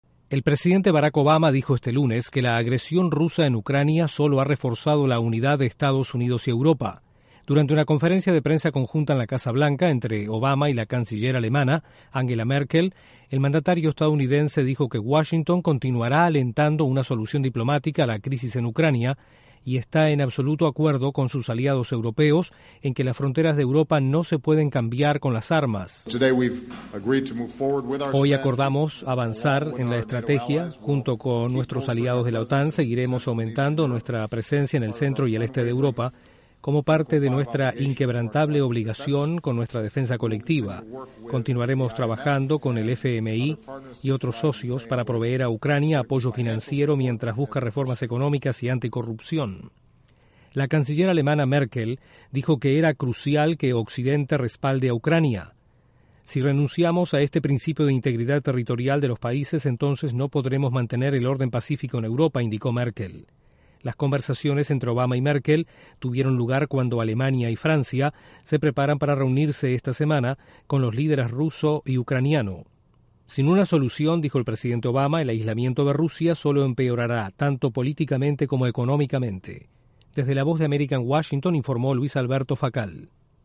El presidente Barack Obama dice que la agresión rusa en Ucrania ha reforzado la unidad de EE.UU. con Europa. Desde la voz de América en Washington